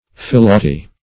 Search Result for " philauty" : The Collaborative International Dictionary of English v.0.48: Philauty \Phil"au*ty\, n. [Gr. filayti`a; fi`los loving + a'yto`s self.] Self-love; selfishness.